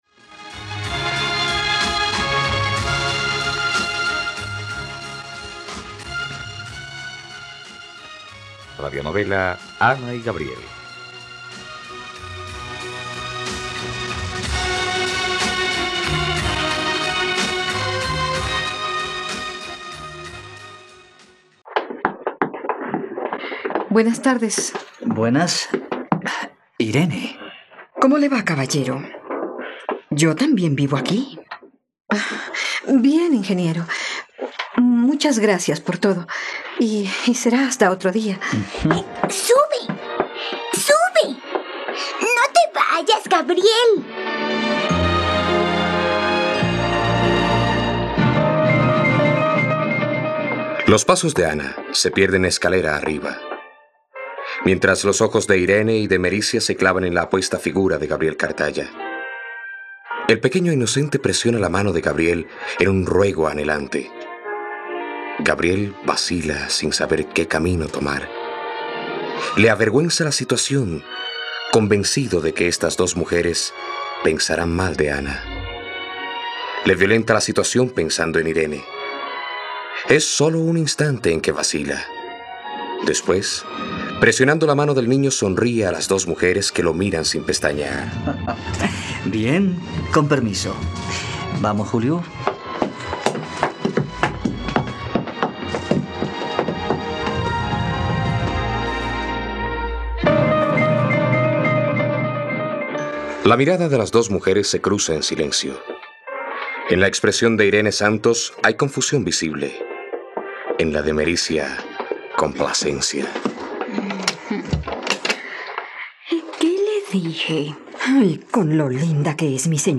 ..Radionovela. Escucha ahora el capítulo 26 de la historia de amor de Ana y Gabriel en la plataforma de streaming de los colombianos: RTVCPlay.